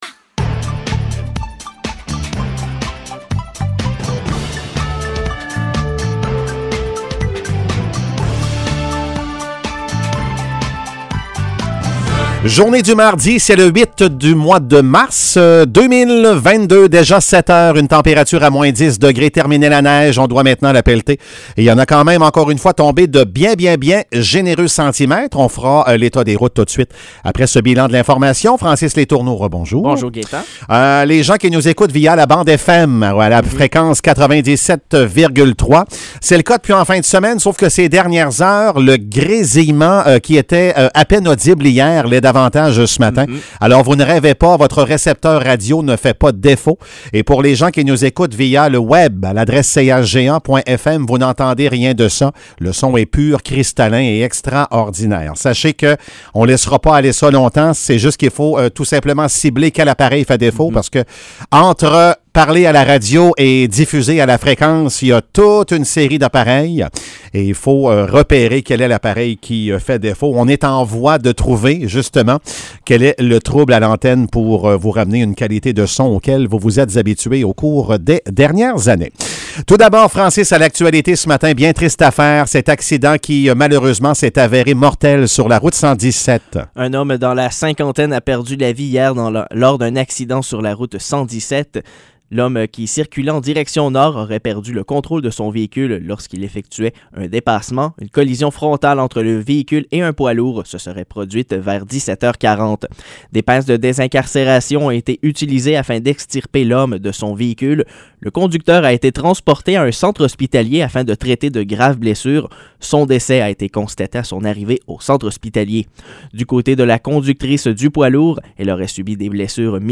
Nouvelles locales - 8 mars 2022 - 7 h